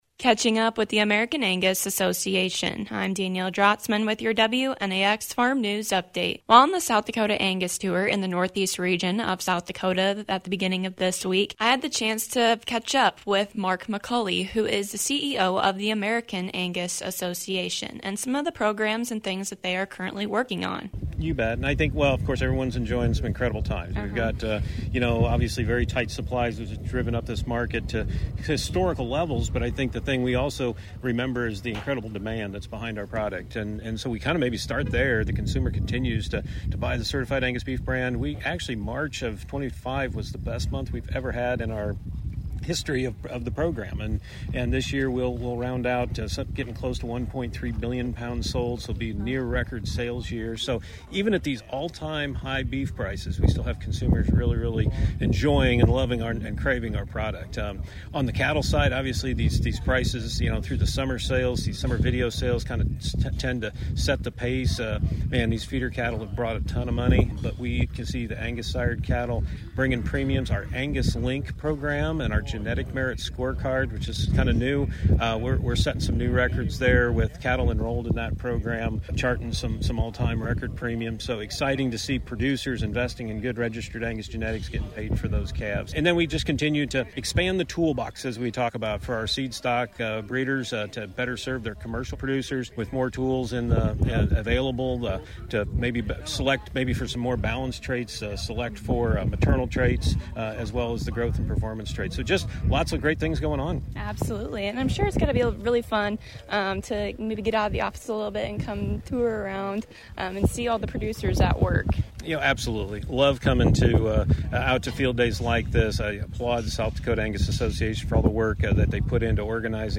While on the SD Angus Association Tour